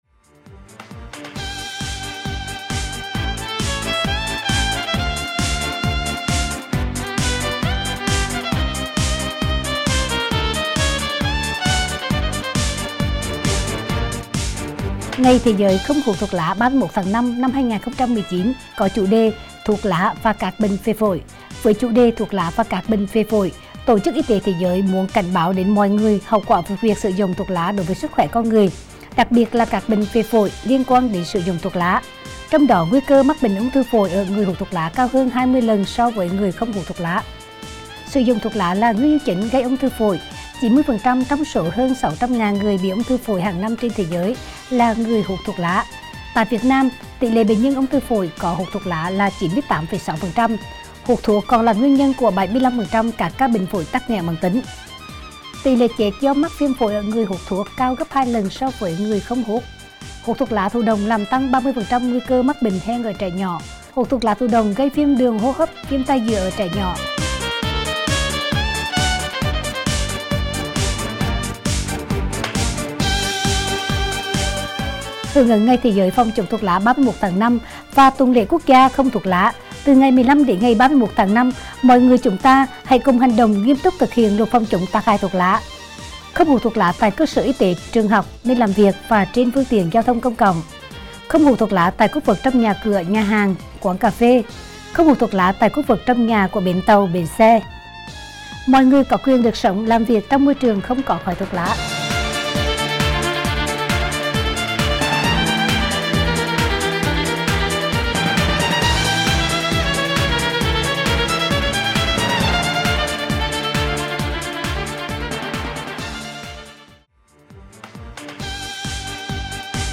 Thông điệp phát thanh phòng chống tác hại thuốc lá năm 2019